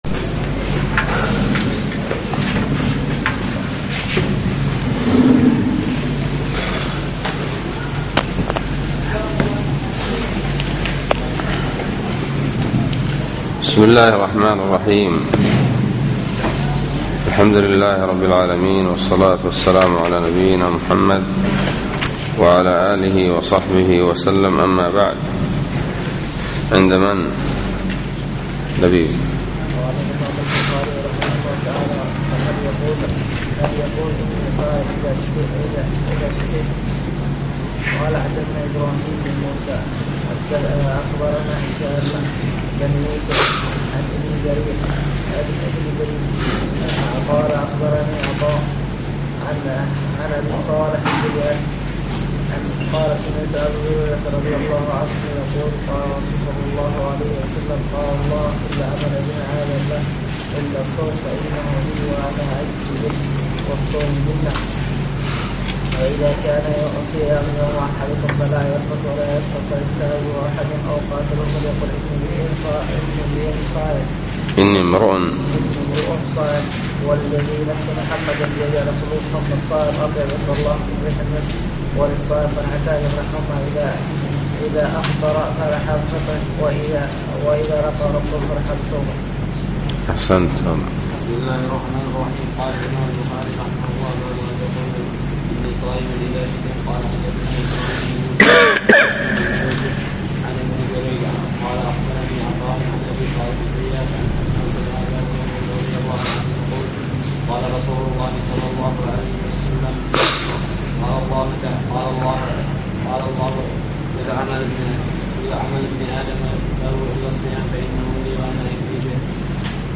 الدرس الثامن : باب الصوم لمن خاف على نفسه العزوبة